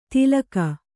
♪ tilaka